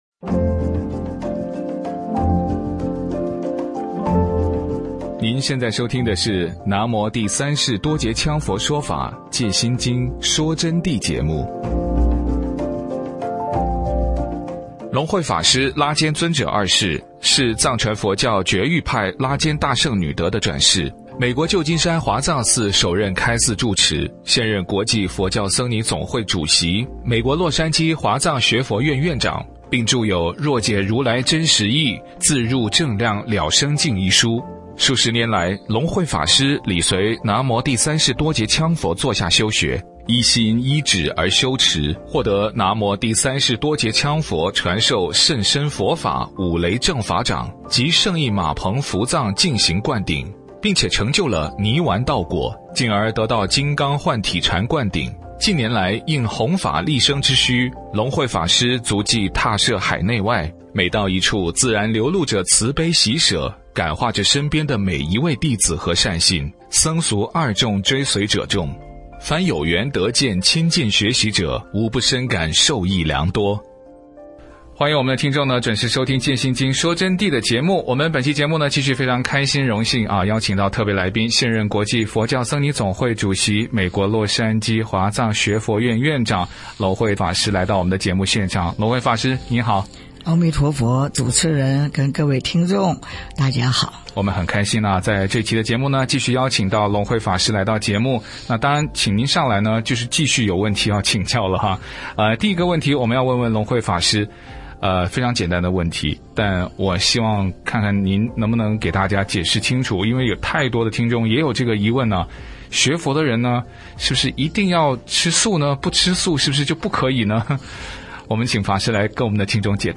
佛弟子访谈（三十二）浅谈学佛吃素、不杀生、四无量心、佛法在世间不离世间觉、八风吹不动和对佛陀觉量概念的理解